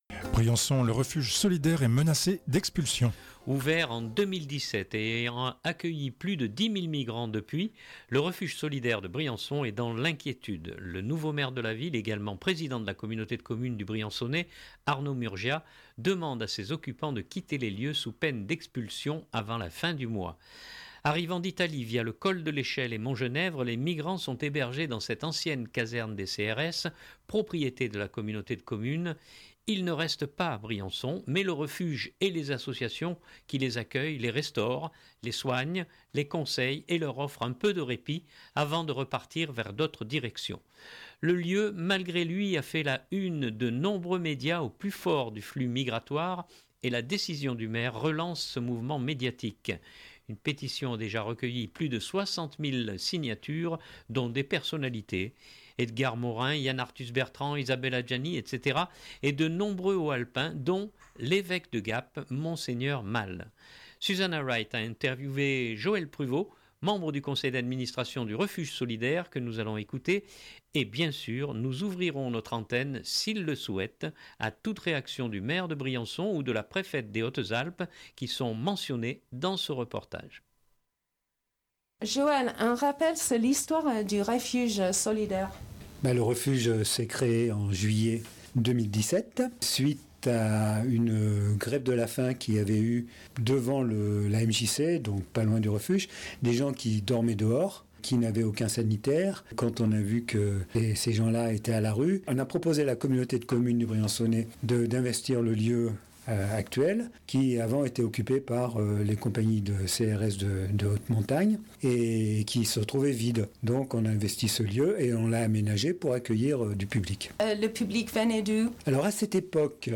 Et bien sûr, nous ouvrirons notre antenne, s’ils le souhaitent à toute réaction du maire de Briançon ou de la Préfète des Hautes-Alpes qui sont mentionnés dans ce reportage.